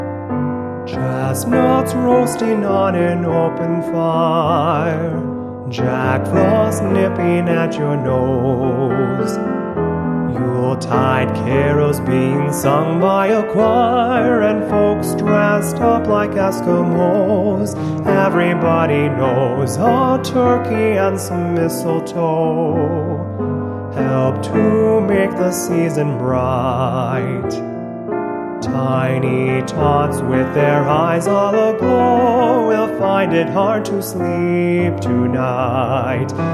arranged for piano and light instrumentals